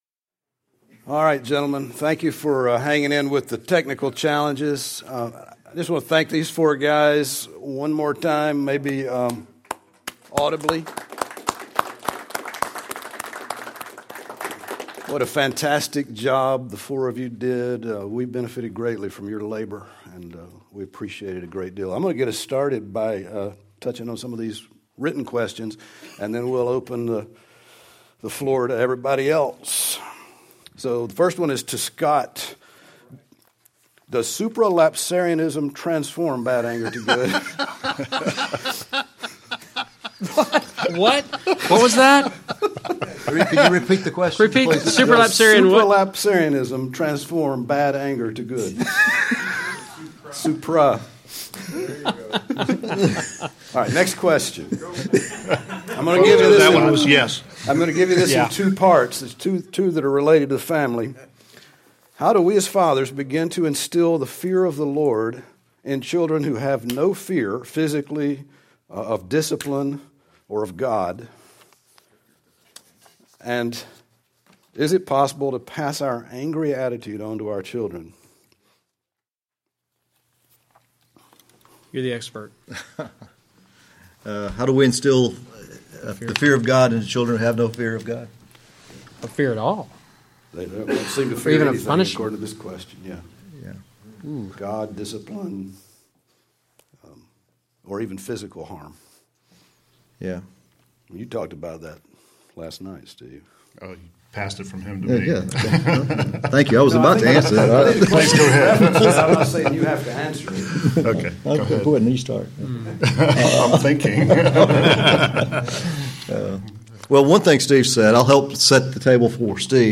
Session 5 Q&A - Panel